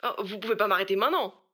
VO_ALL_Interjection_10.ogg